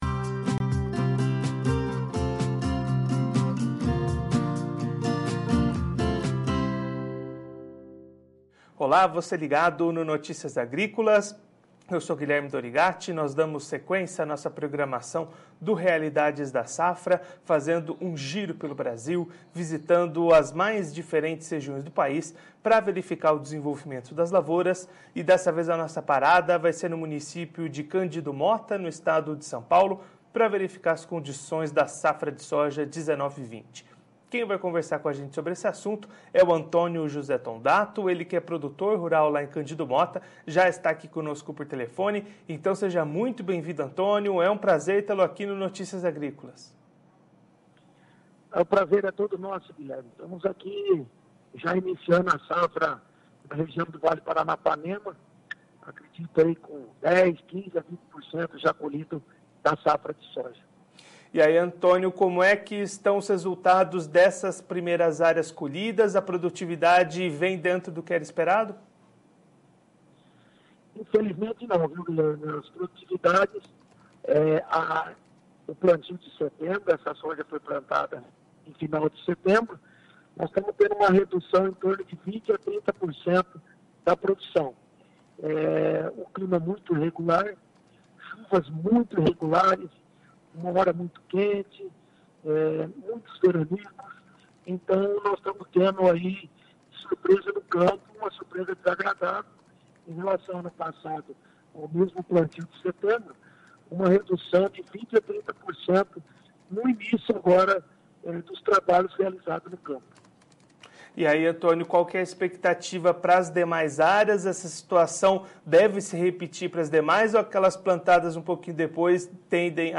Confira a íntegra da entrevista com o produtor rural de Cândido Mota/SP no vídeo.